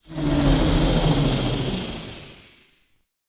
ht-locomotive-end-engine.ogg